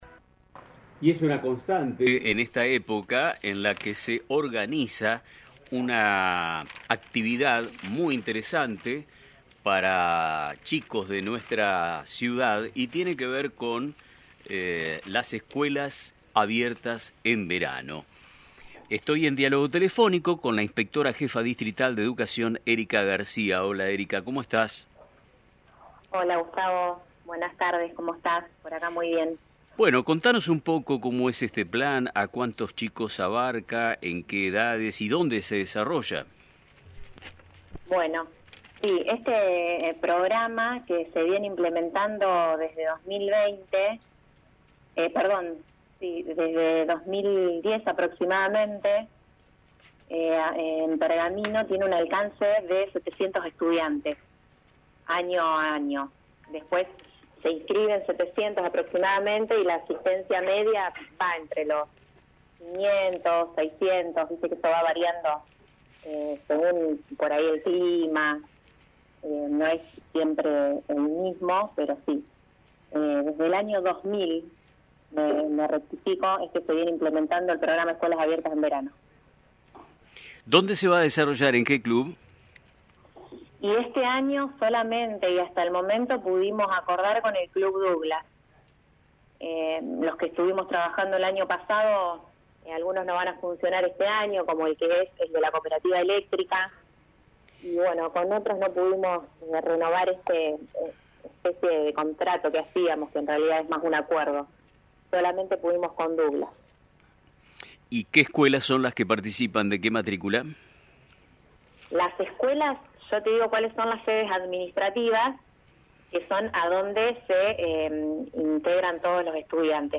En una reciente entrevista en el programa Nuestro Tiempo, emitido por LT35 Radio Mon Pergamino